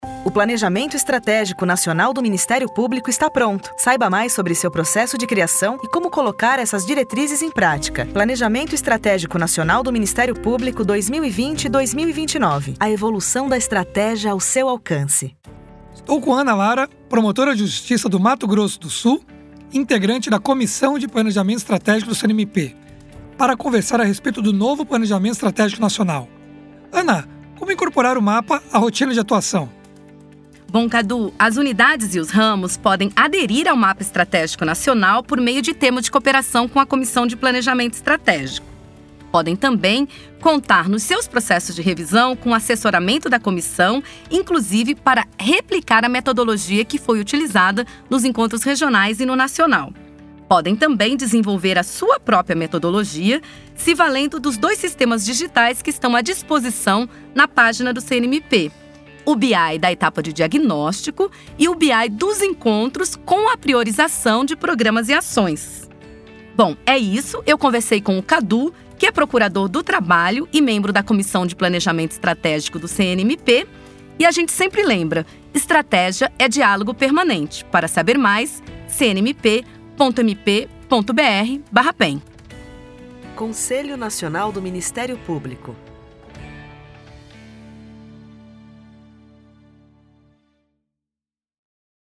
A iniciativa conta com cards para as redes sociais, gif, cartaz, e-mail marketing e podcasts com entrevistas com membros que participaram do projeto.